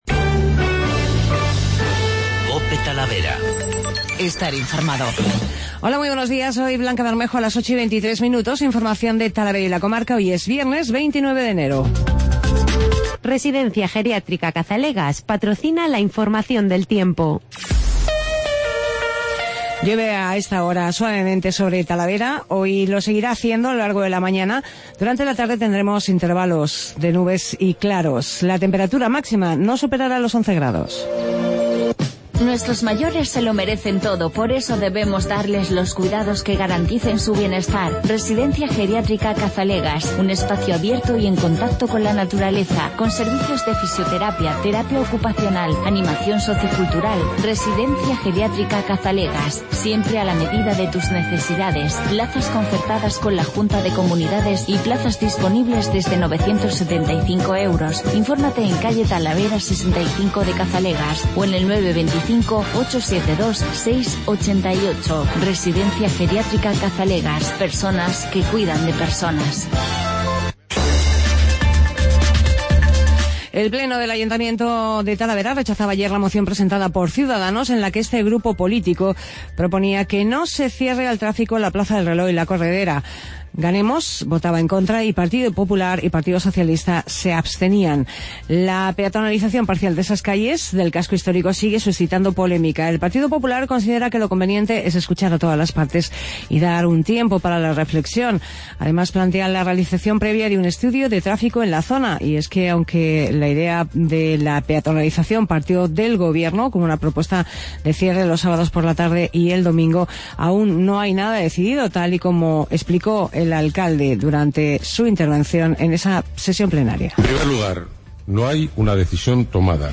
Talavera de la Reina